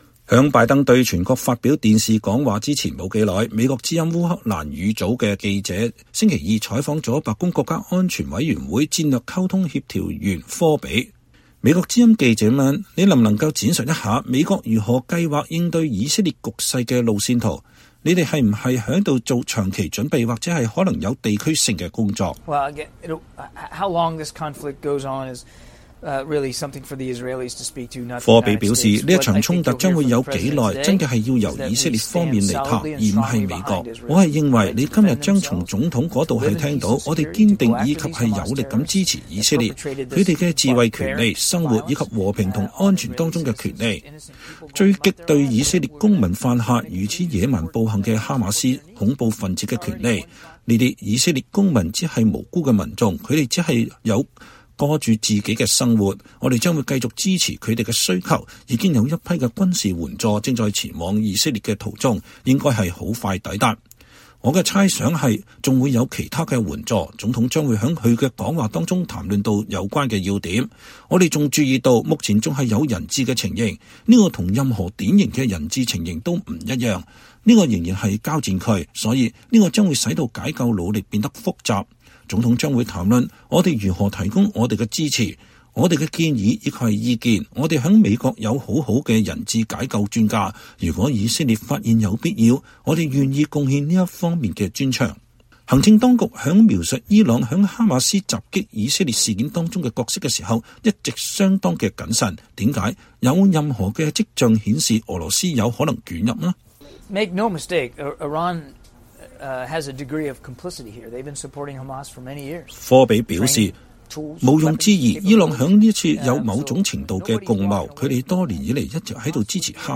VOA專訪：國安會發言人說未見伊朗與俄羅斯直接捲入哈馬斯攻擊的跡象
白宮國家安全委員會戰略協調員約翰‧科比接受美國之音訪問。(2023年10月10日)
在拜登對全國發表電視講話前不久，美國之音(VOA)烏克蘭語組記者周二採訪了白宮國家安全委員會戰略溝通協調員約翰·科比(John Kirby)。